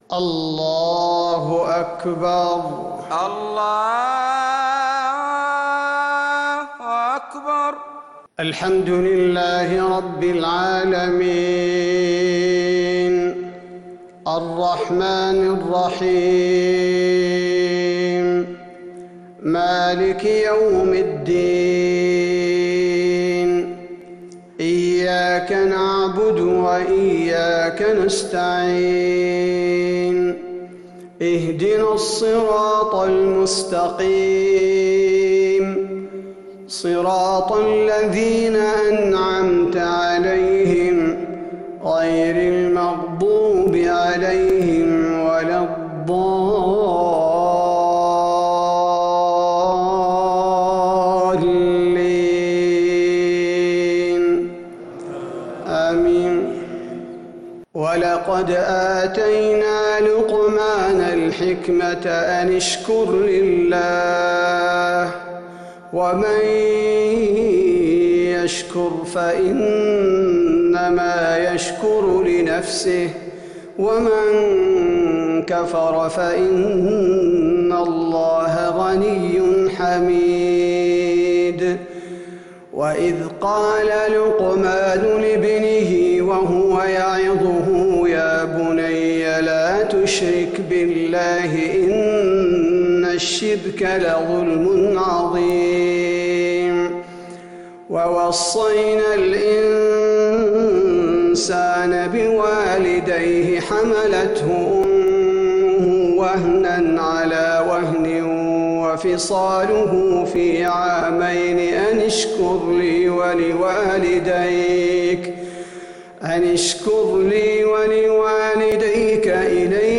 صلاة العشاء للقارئ عبدالباري الثبيتي 2 ربيع الأول 1442 هـ
تِلَاوَات الْحَرَمَيْن .